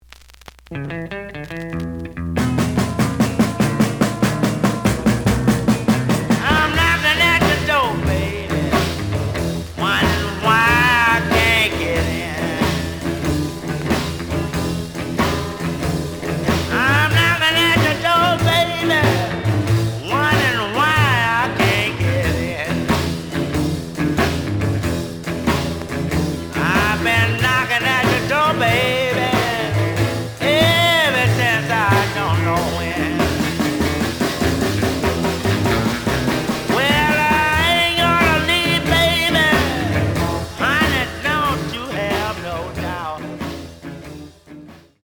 The audio sample is recorded from the actual item.
●Genre: Blues
Slight edge warp.